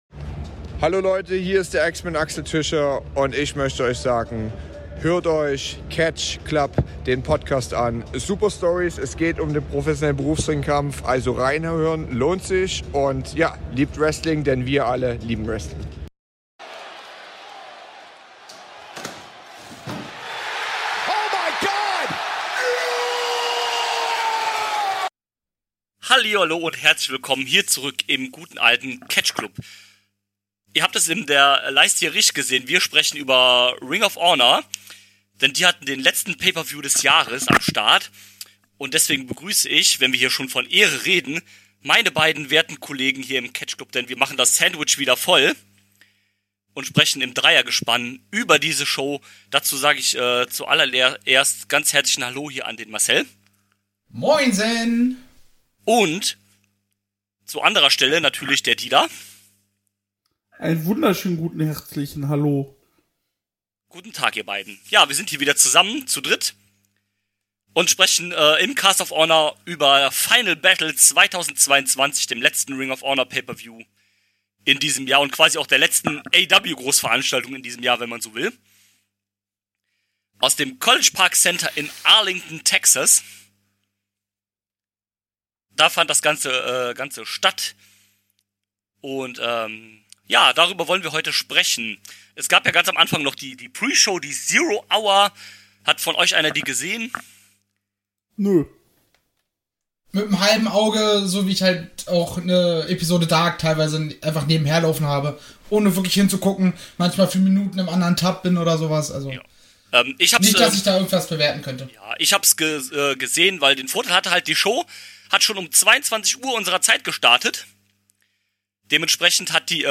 Ring of Honor hat ihren letzten PPV des Jahres über die Bühne gebracht und dass war uns Grund genug, in der dreier Runde aufzunehmen. Wir sprechen natürlich über den PPV und wie ist mit ROH im TV 2023 weitergehen wird.